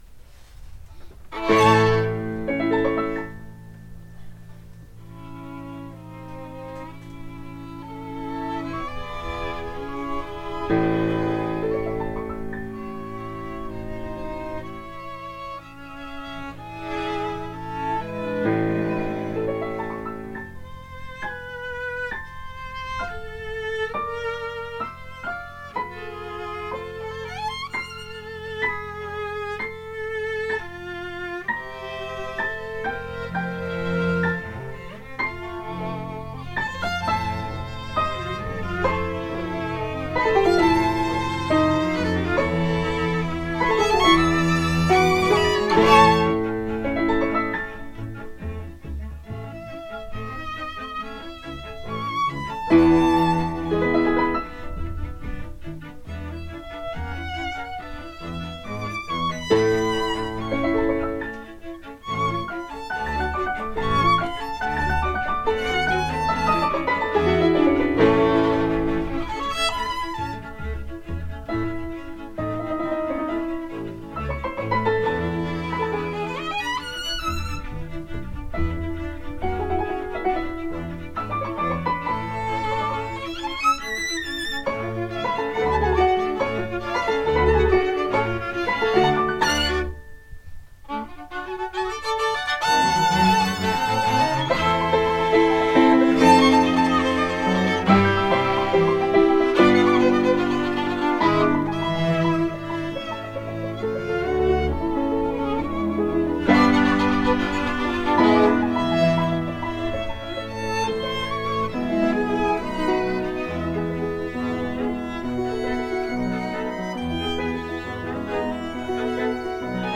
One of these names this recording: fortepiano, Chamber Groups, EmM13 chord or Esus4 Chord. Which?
Chamber Groups